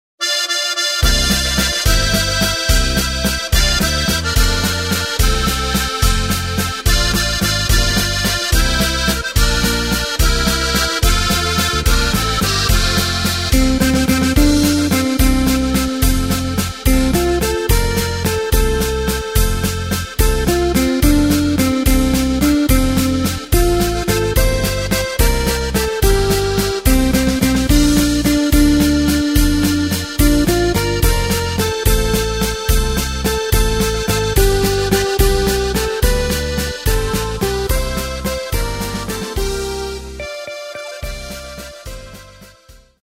Takt:          3/4
Tempo:         216.00
Tonart:            F
Walzer aus dem Jahr 2018!